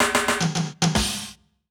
British SKA REGGAE FILL - 07.wav